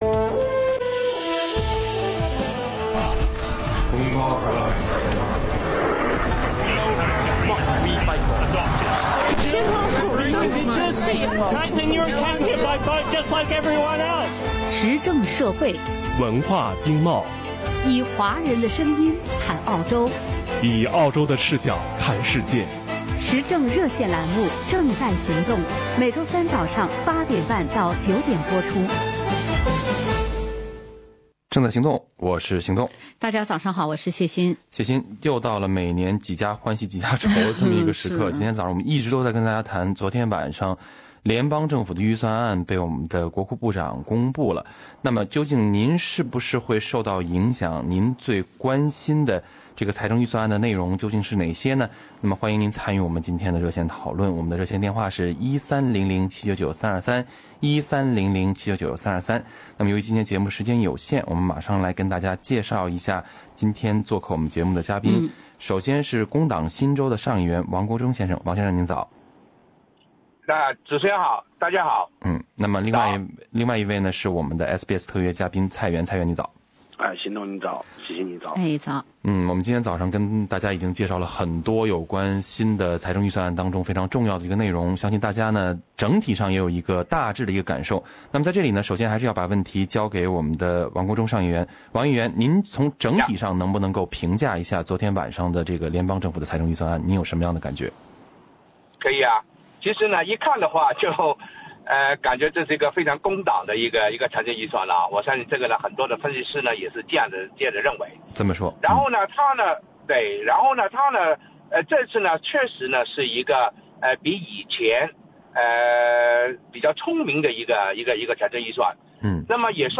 节目点评： 嘉宾王国忠（新州工党上议员）：这个预算很有工党风格，也有点过于乐观。